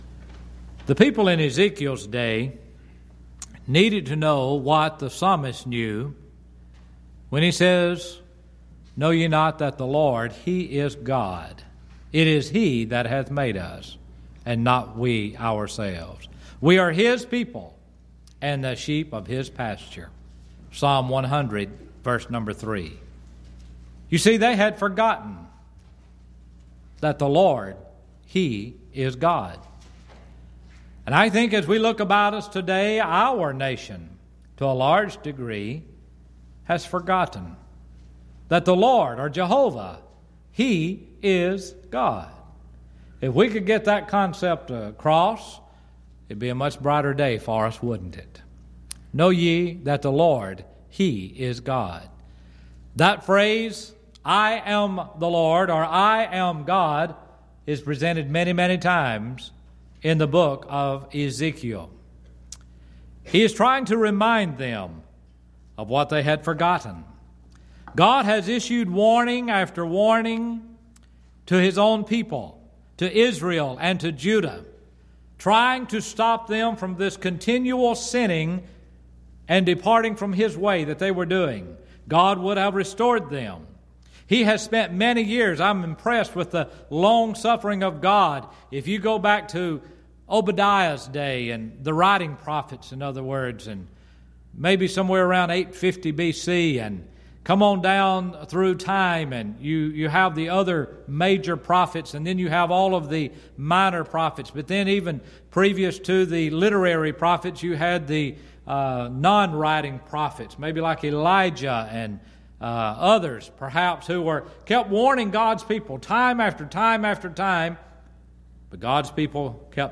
Event: 10th Annual Schertz Lectures
lecture